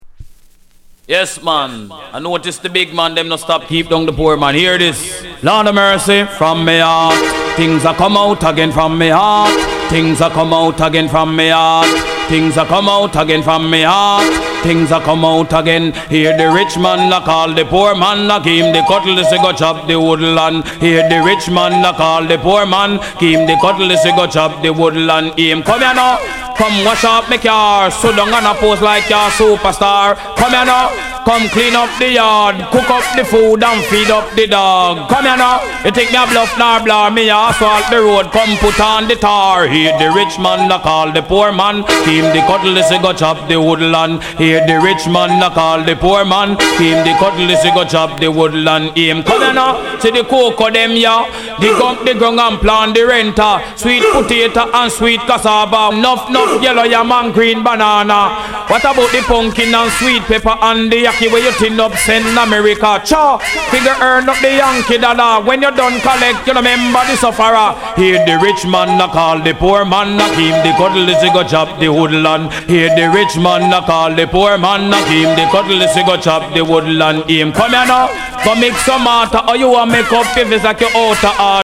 Genre: Reggae/Dancehall